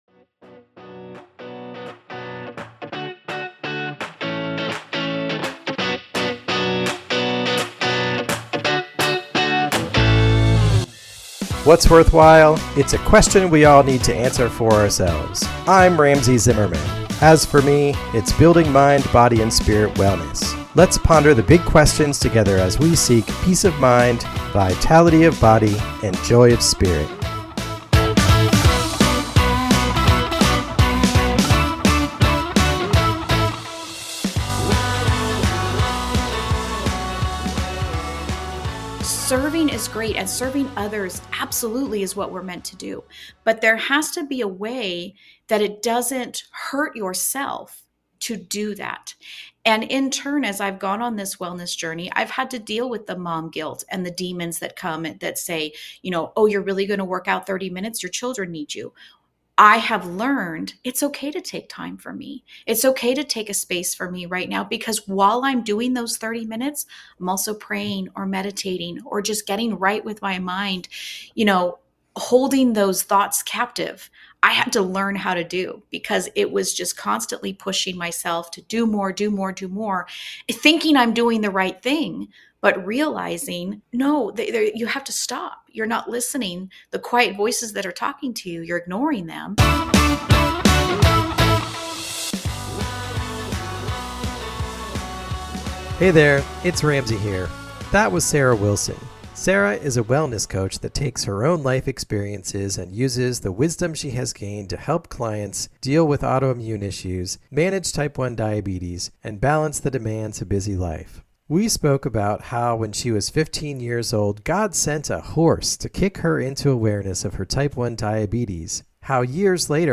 Filled with wild stories and retrospective laughs, this is an episode not to miss.